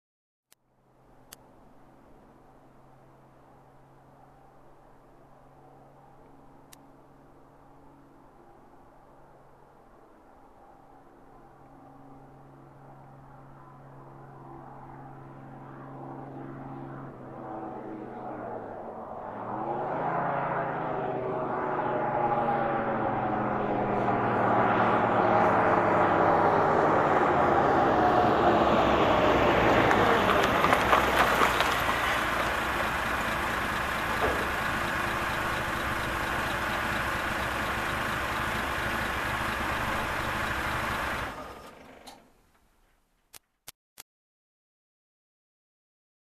ГРУЗОВИК-ВОЕННЫЙ ТРАНСПОРТЕР ОРУЖИЯ: ВНЕШ.: ПРИБЛИЖАЕТСЯ СЛЕВА, ТРОГАЕТСЯ, ВЫКЛЮЧЕНИЕ
• Категория: Переключение коробки передач
• Качество: Высокое